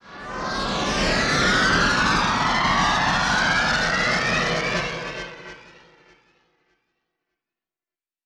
Index of /90_sSampleCDs/Spectrasonics - Bizarre Guitar/Partition H/07 SCRAPE SW